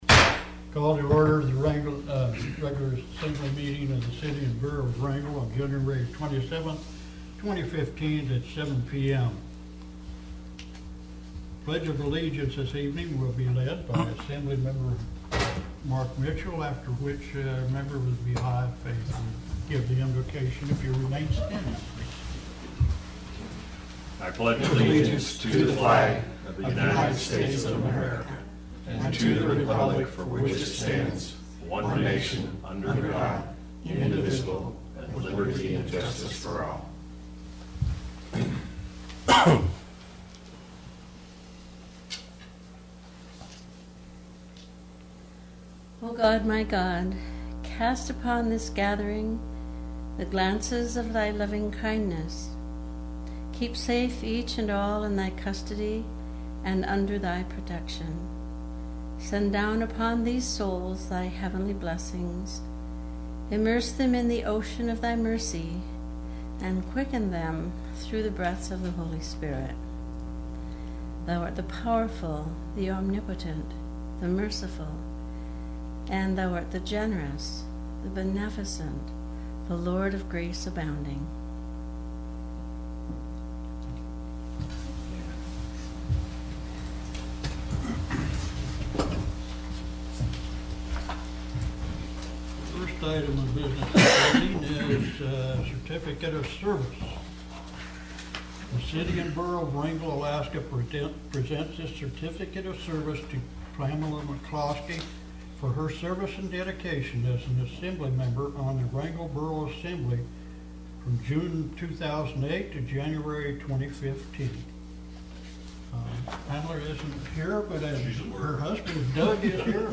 Wrangell's Borough Assembly met for a regular meeting Tuesday, Jan. 27 in the Assembly Chambers.